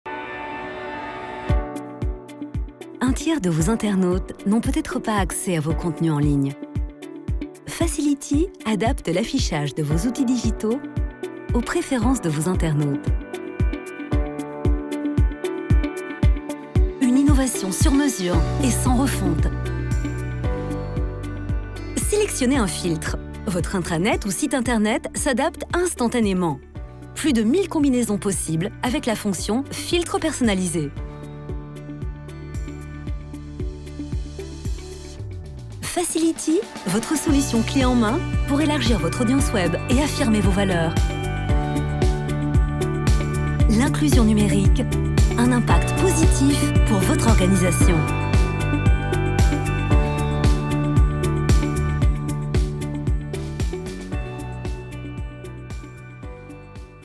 Comercial, Natural, Travieso, Versátil, Empresarial
Explicador